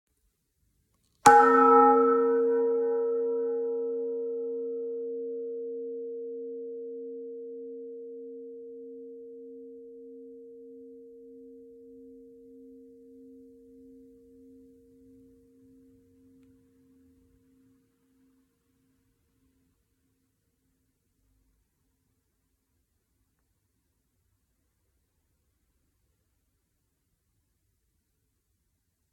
Die verschiedenen Teiltöne der Glocken hört man an allen Anschlagspunkten, jedoch in jeweils unterschiedlicher Intensität.
Anschlagpunkt d [504 KB]
glocke-brauweiler-d.mp3